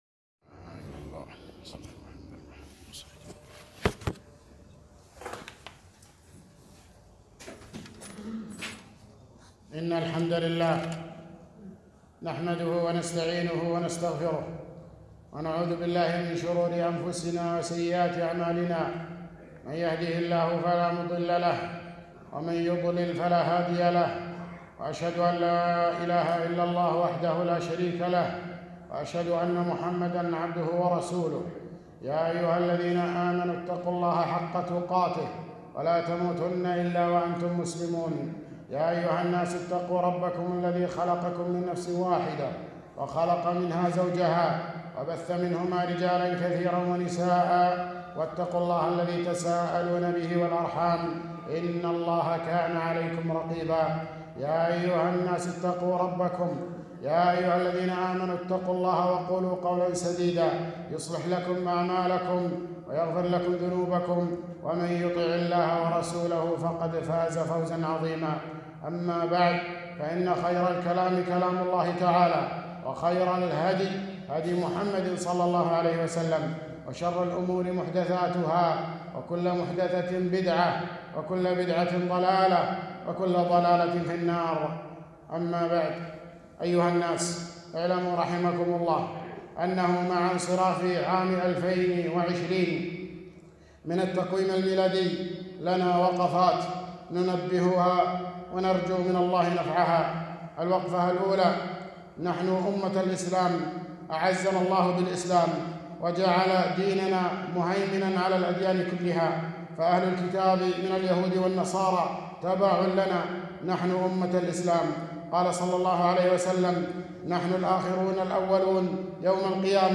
خطبة - وقفات مع انقضاء عام ٢٠٢٠